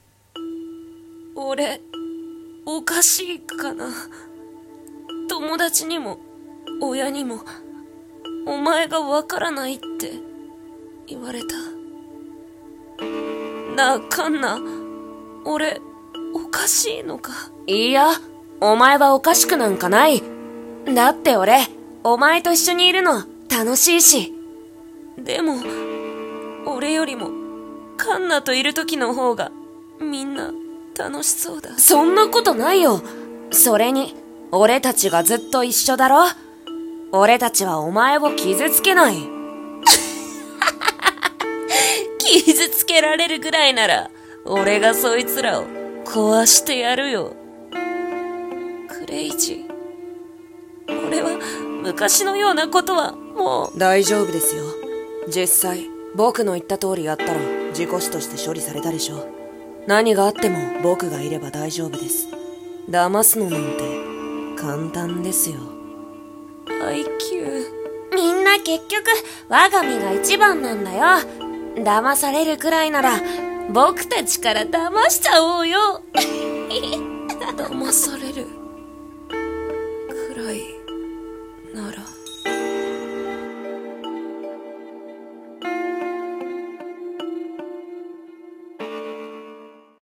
声劇】騙されるくらいなら